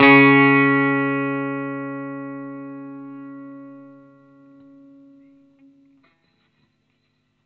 R12 NOTE  CS.wav